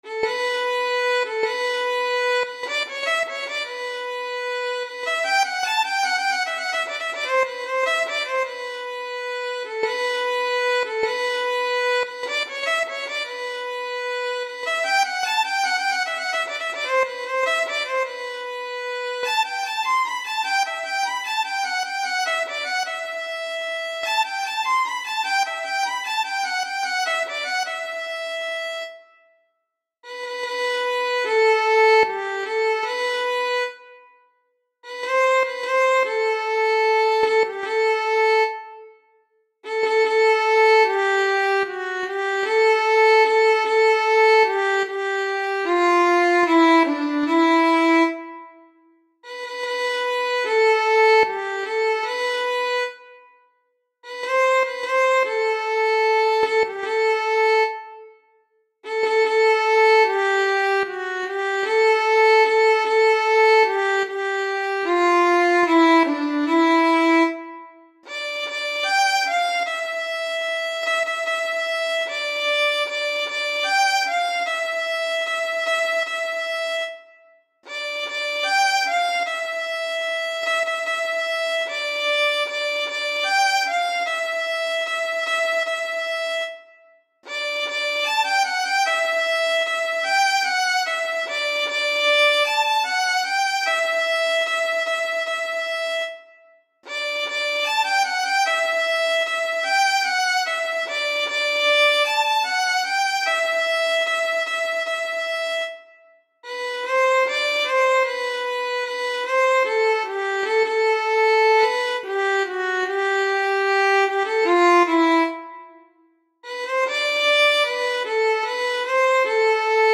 ویولون
تنظیم شده برای ویولن